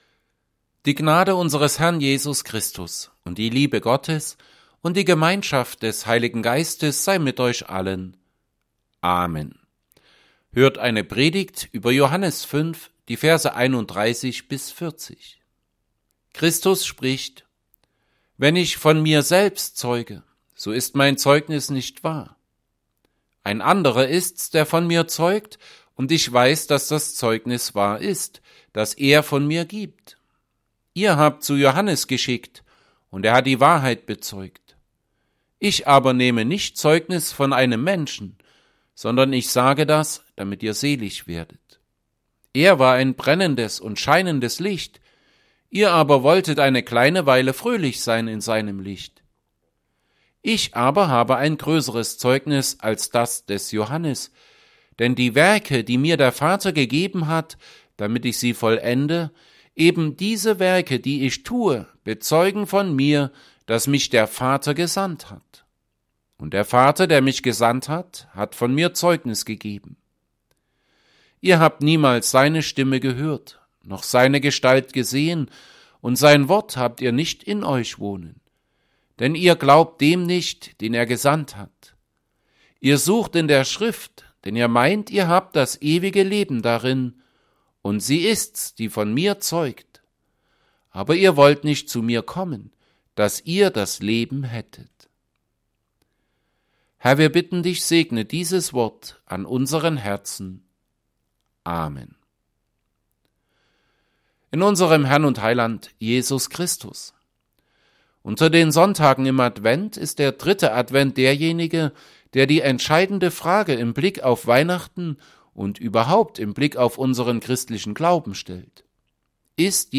Predigt_zu_Johannes_5_31b40.mp3